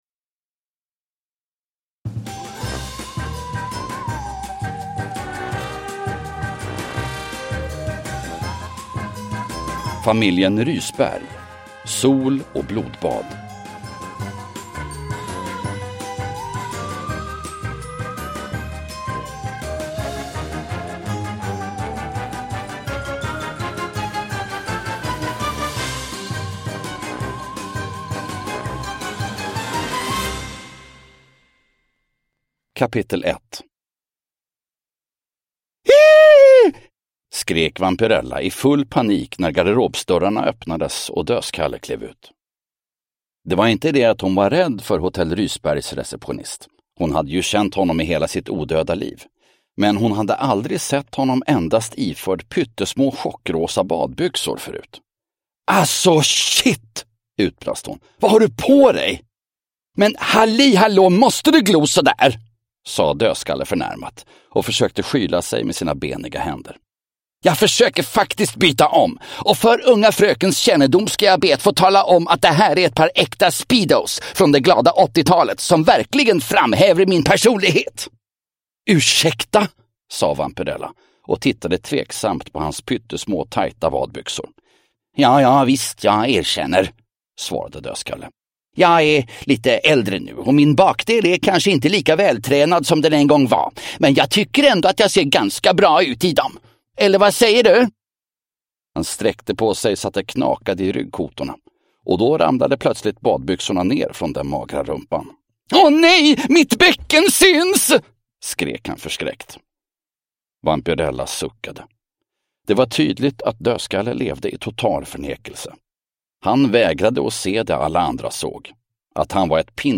Sol och blodbad – Ljudbok – Laddas ner